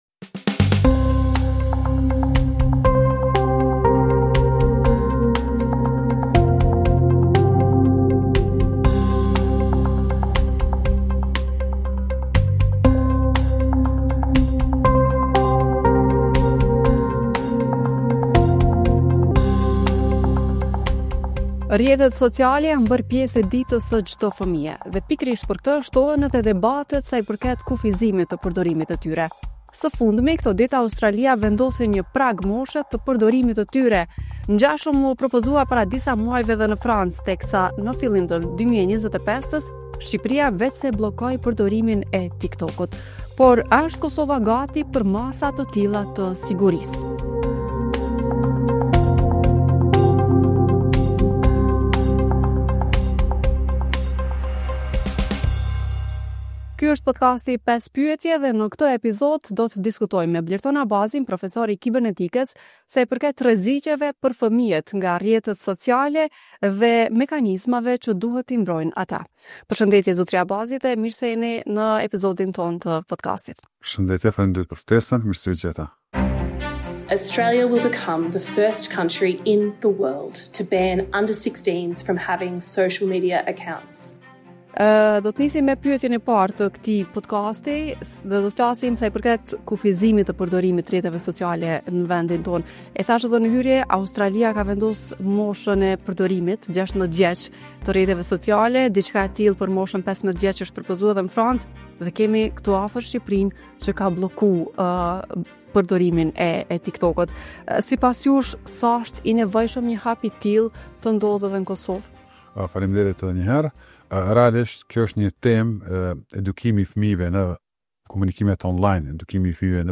5 pyetje është podkast i Radios Evropa e Lirë, ku një temë sqarohet me ndihmën e profesionistëve dhe ekspertëve, përmes përgjigjeve që kapin thelbin. Nga shëndeti e ekonomia, deri te politika dhe ligji, çdo episod sjell bisedë të qartë, verifikim faktesh dhe sqarime të nevojshme.